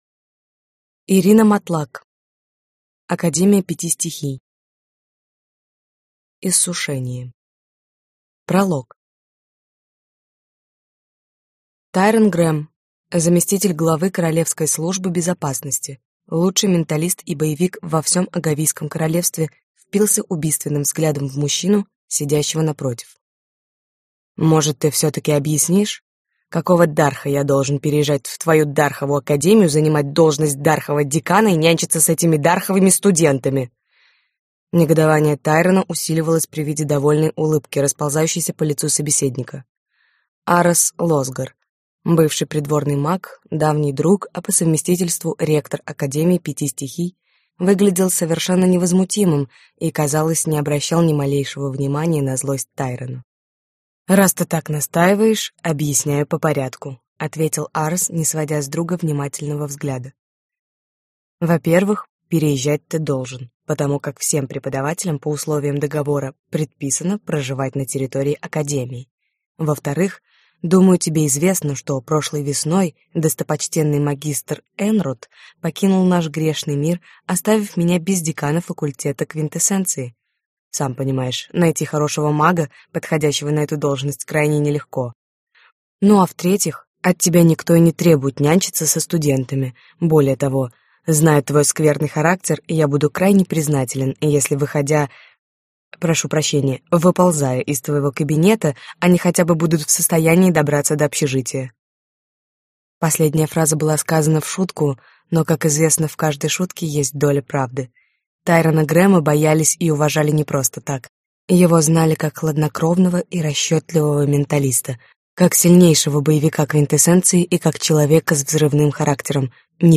Аудиокнига Академия пяти стихий. Иссушение - купить, скачать и слушать онлайн | КнигоПоиск